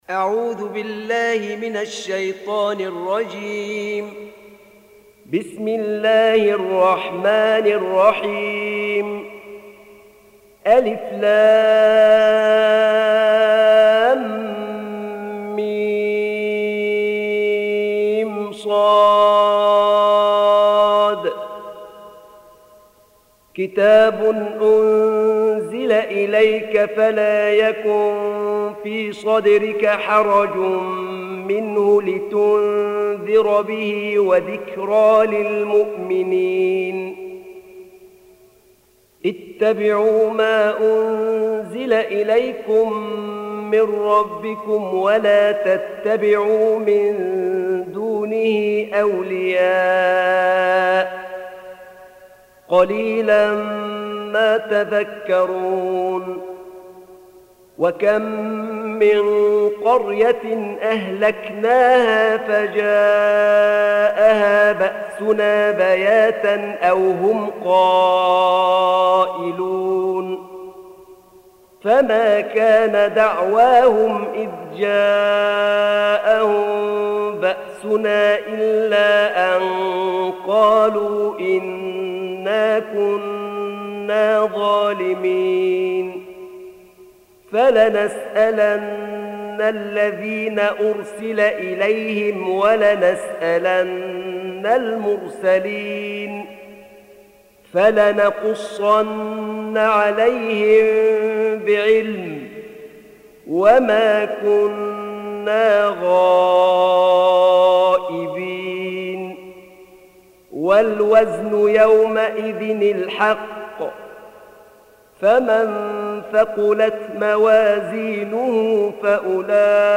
Surah Repeating تكرار السورة Download Surah حمّل السورة Reciting Murattalah Audio for 7. Surah Al-A'r�f سورة الأعراف N.B *Surah Includes Al-Basmalah Reciters Sequents تتابع التلاوات Reciters Repeats تكرار التلاوات